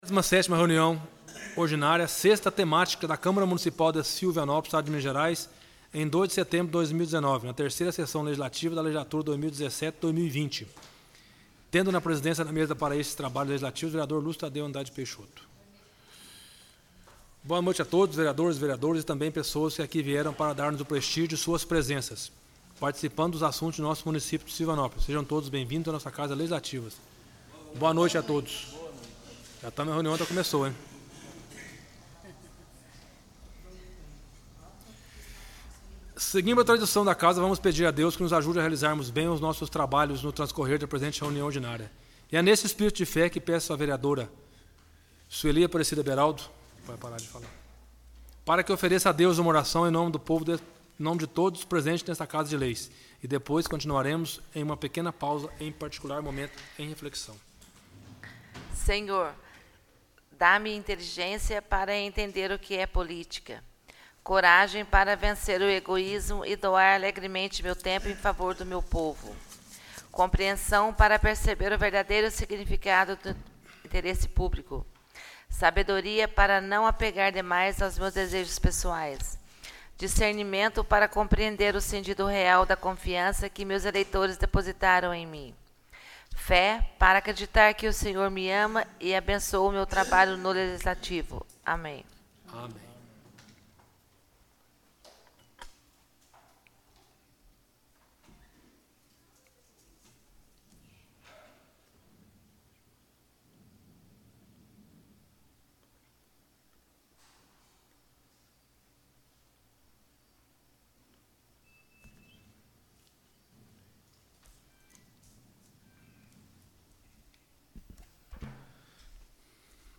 Áudio - Parte 1 - 27ª Reunião Ordinária de 2019 - 6ª Temática